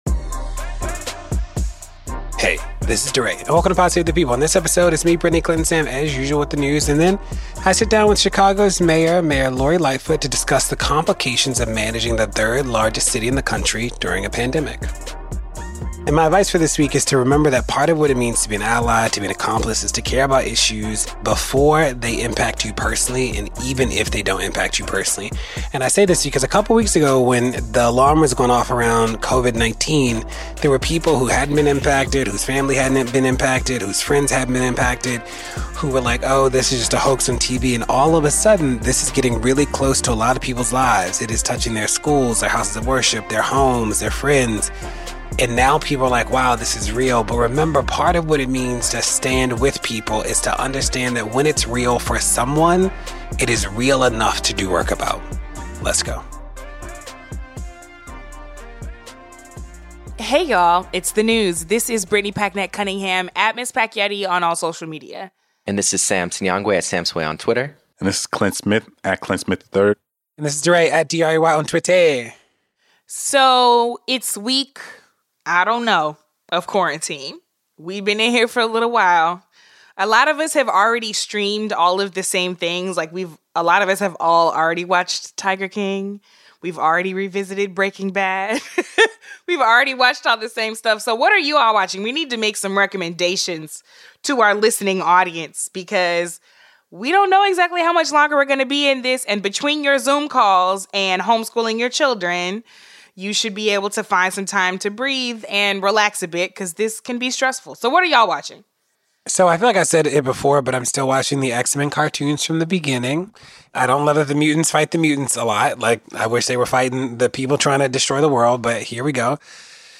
Then, DeRay sits down with Chicago Mayor Lori Lightfoot to examine the response of America's third largest city to the current crisis.